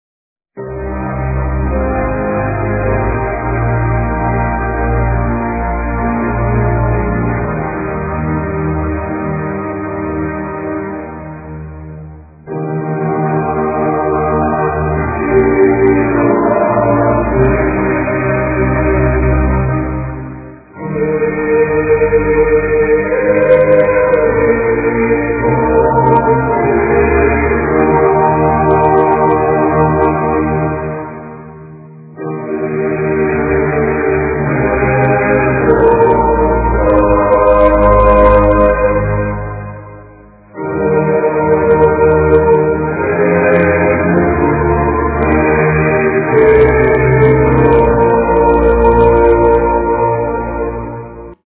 Coral Gregoriano
011.coral.gregoriano.wav